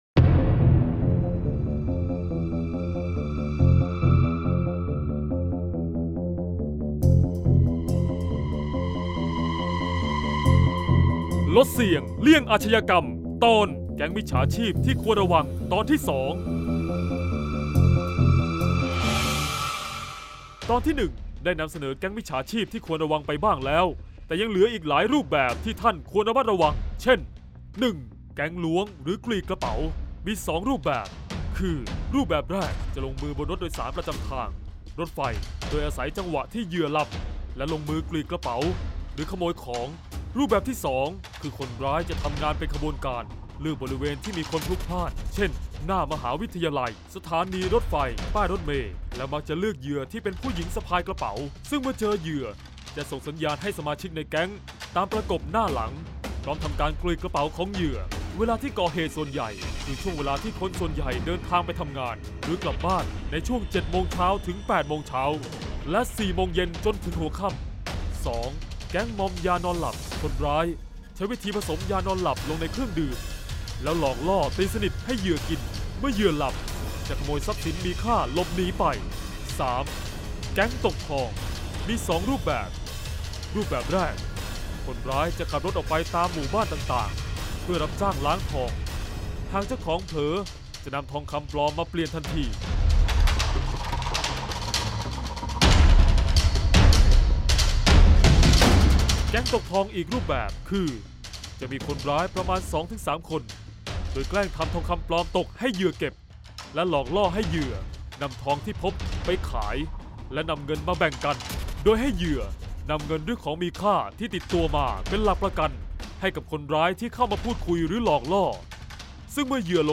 เสียงบรรยาย ลดเสี่ยงเลี่ยงอาชญากรรม 34-แก๊งมิชฉาชีพที่ควรระวัง